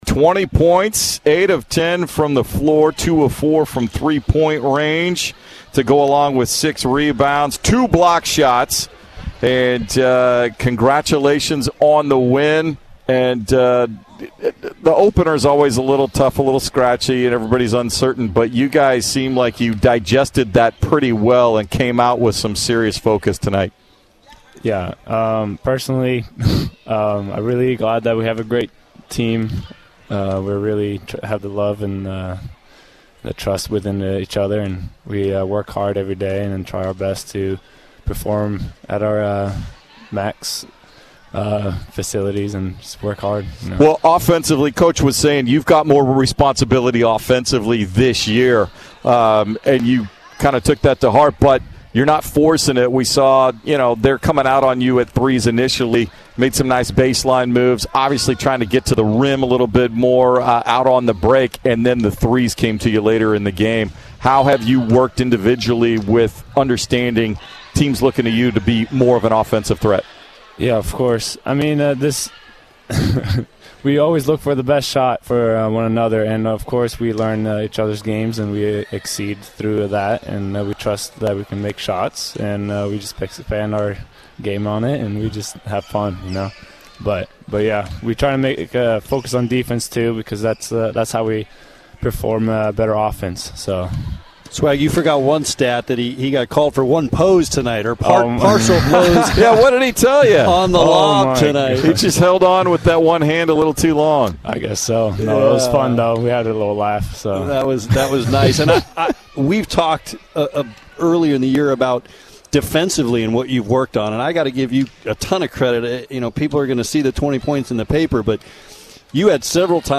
Men's Basketball Radio Interviews